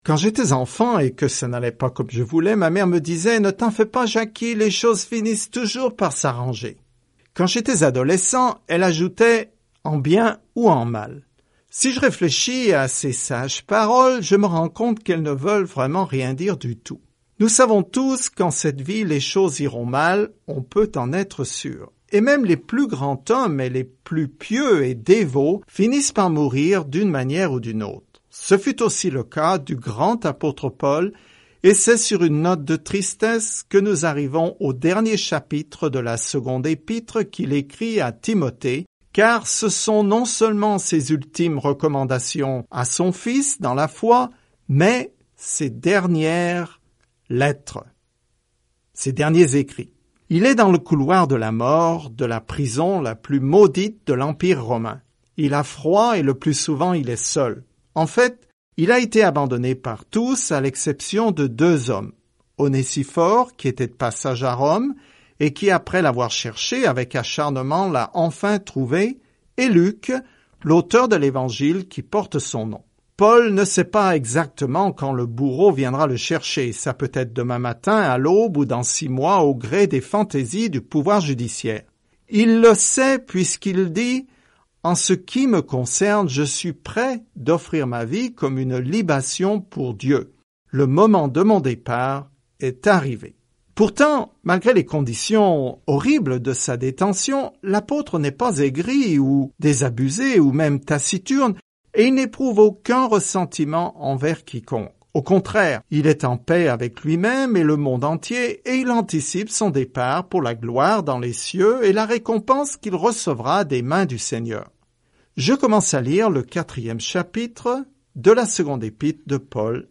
Écritures 2 Timothée 4:1-6 Jour 6 Commencer ce plan Jour 8 À propos de ce plan La deuxième lettre à Timothée appelle le peuple de Dieu à défendre la parole de Dieu, à la garder, à la prêcher et, si nécessaire, à souffrir pour elle. Parcourez quotidiennement 2 Timothée en écoutant l’étude audio et en lisant des versets sélectionnés de la parole de Dieu.